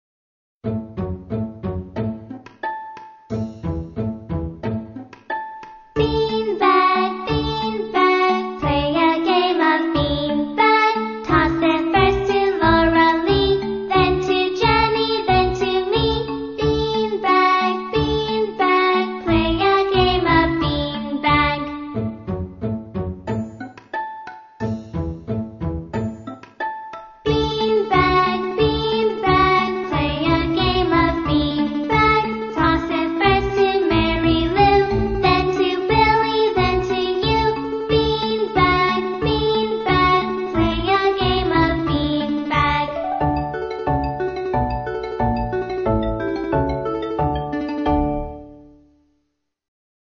在线英语听力室英语儿歌274首 第16期:Beanbag,beanbag的听力文件下载,收录了274首发音地道纯正，音乐节奏活泼动人的英文儿歌，从小培养对英语的爱好，为以后萌娃学习更多的英语知识，打下坚实的基础。